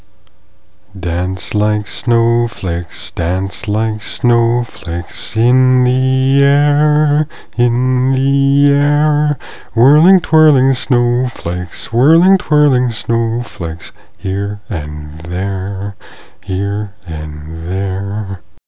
Artefact Two: a snowflake song
My voice is a little rusty.